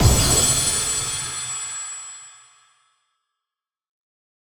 Synth Impact 04.wav